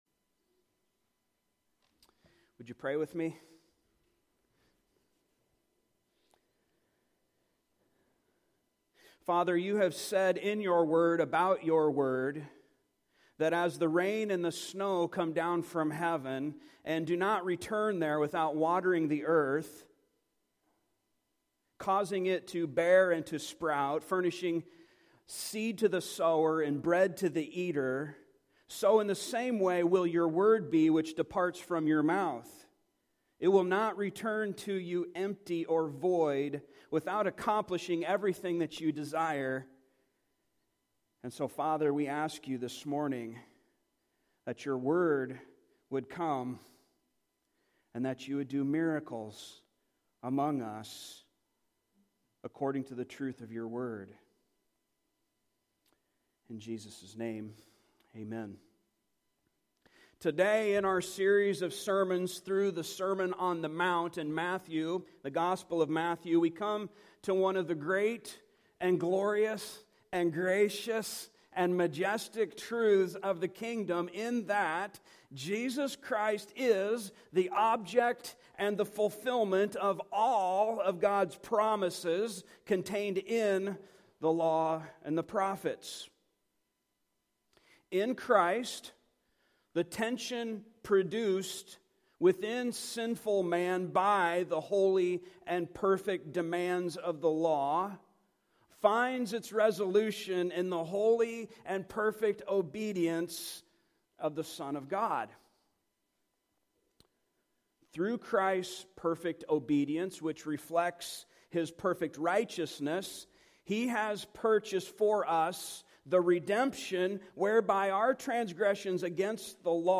Series: Sermon on the Mount
Service Type: Sunday Morning